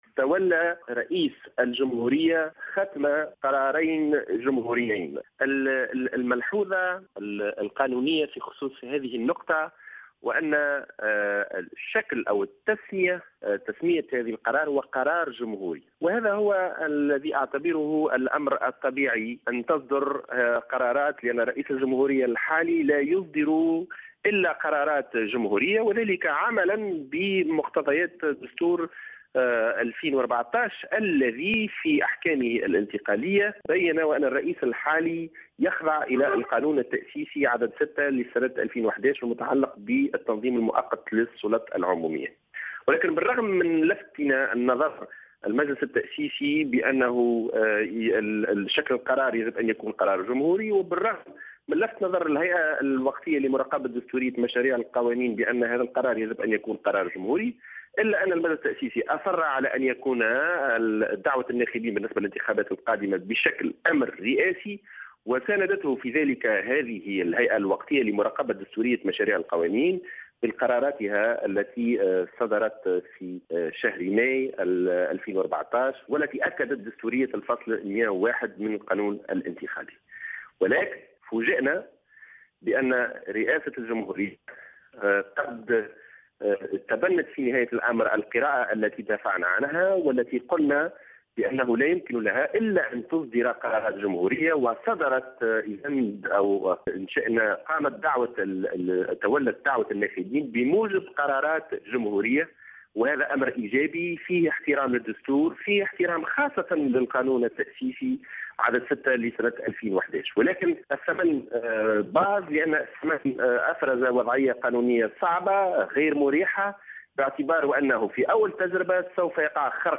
une déclaration accordée à Jawhara FM